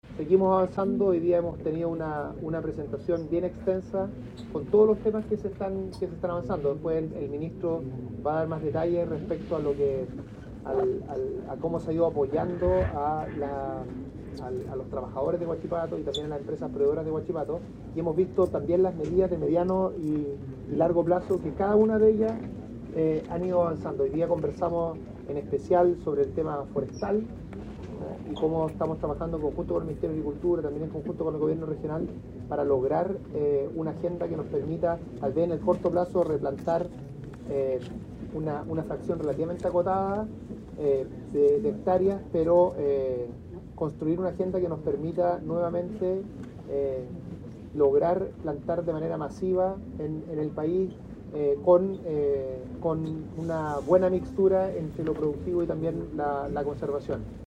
La cita tuvo lugar en el auditorio de la Cámara Chilena de la Construcción en Concepción y forma parte del despliegue integral del Gobierno para enfrentar el impacto económico tras el cierre de la Compañía Siderúrgica Huachipato.
Al cierre de la actividad, el ministro Grau reforzó la estructura de gobernanza del plan, destacando la participación activa de actores clave en la toma de decisiones.